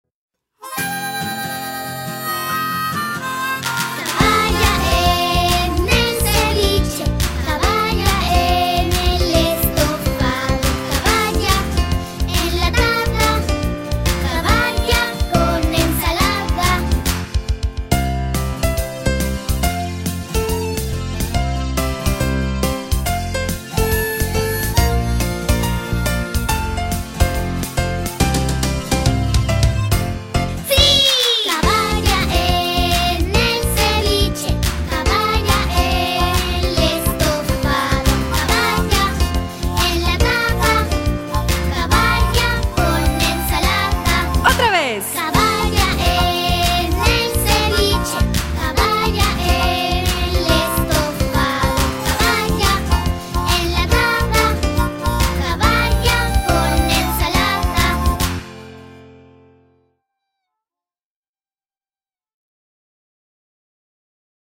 (karaoke)